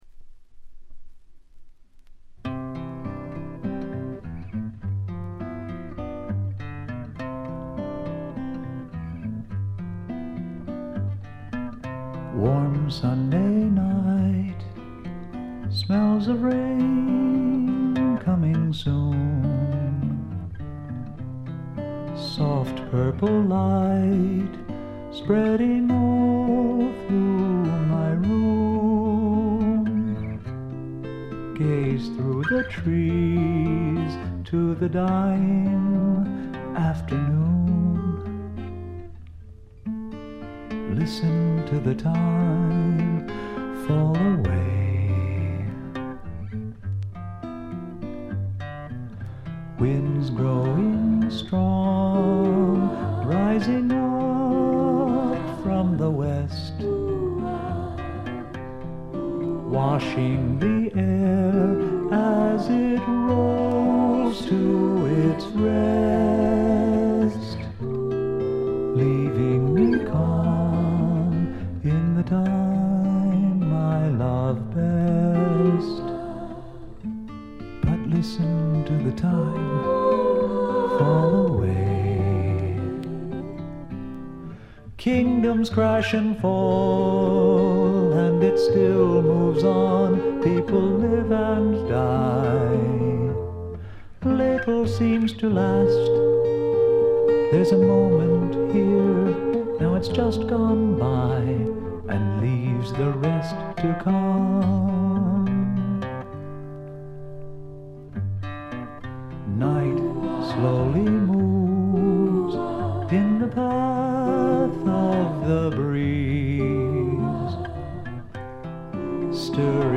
ごくわずかなノイズ感のみ。
で内容はというと英米の良さを併せ持った素晴らしすぎるフォーク／フォークロックです。
試聴曲は現品からの取り込み音源です。